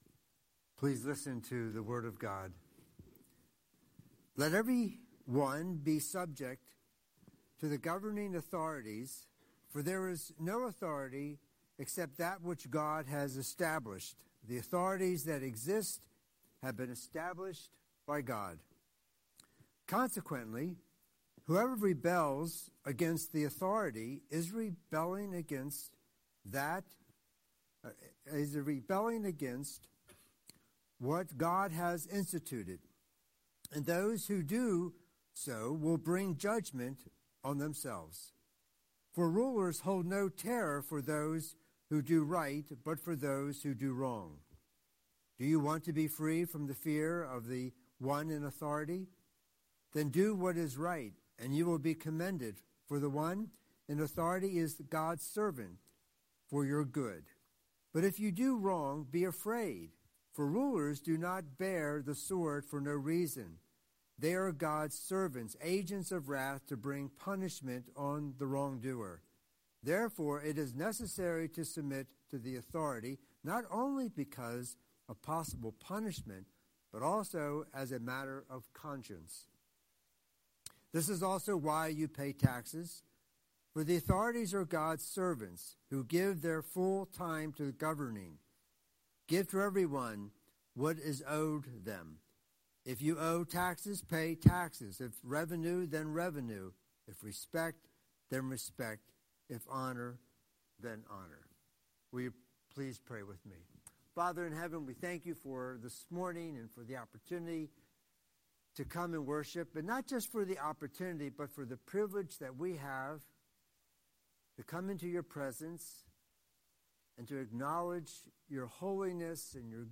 Scripture: Romans 13:1–7 Series: Sunday Sermon